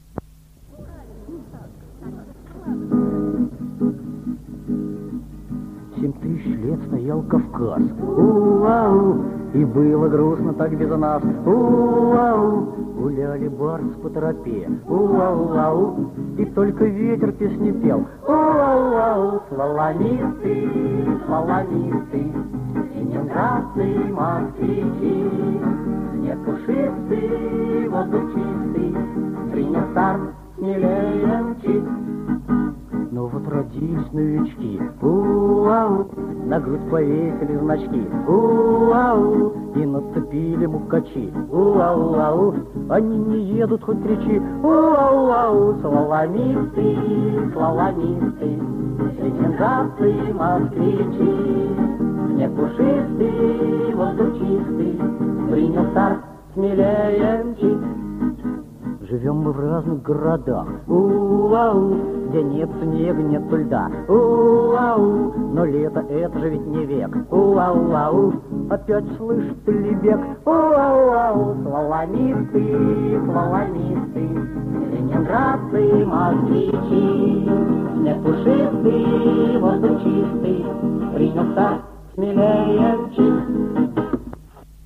Аудиозаписи Третьего Московского конкурса студенческой песни
ДК МЭИ. 7 декабря 1961 года.
Неустановленный солист и женский квартет МГПИ под гитару